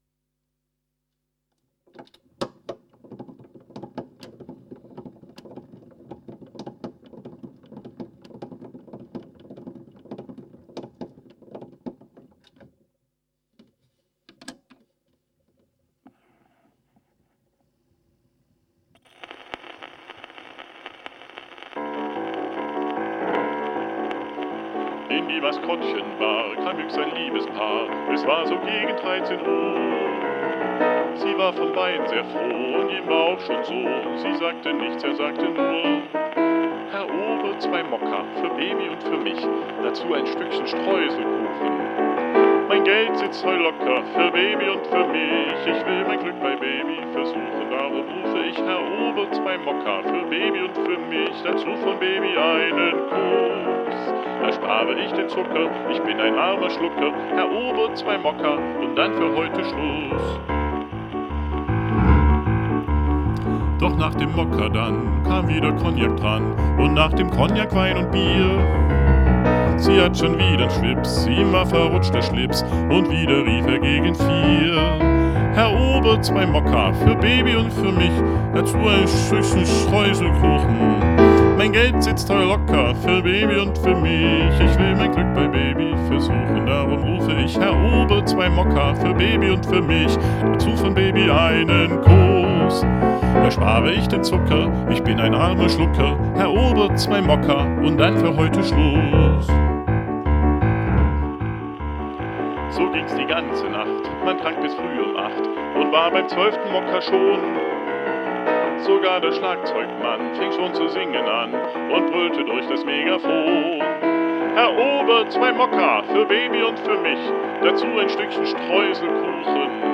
z.T. mit "Grammophon-Special-Effect"